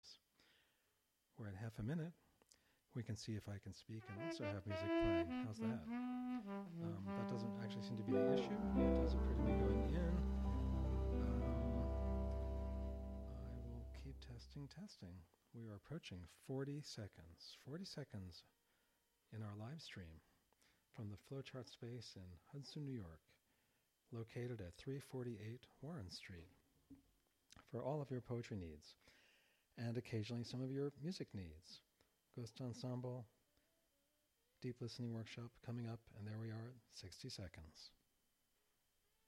Live from The Flow Chart Foundation